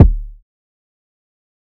Kick (KickInFace2).wav